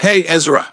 synthetic-wakewords
ovos-tts-plugin-deepponies_Sweetie Belle_en.wav